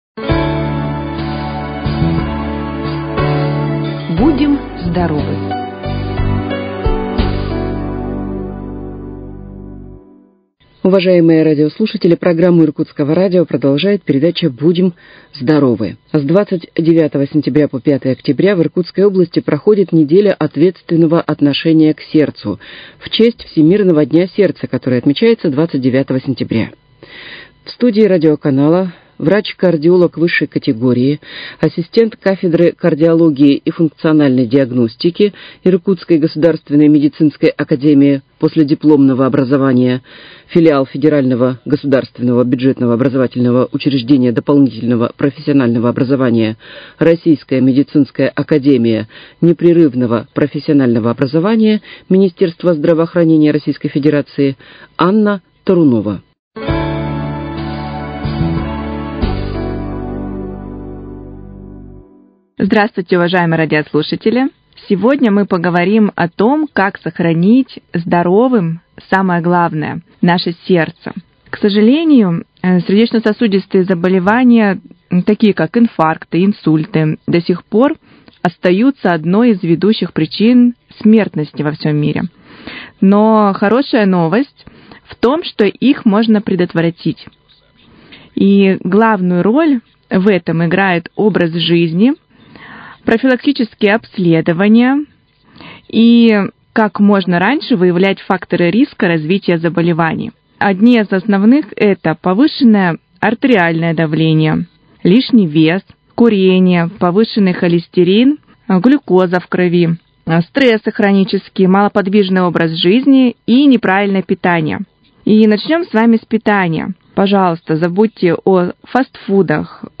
В студии Иркутского радио врач кардиолог высшей категории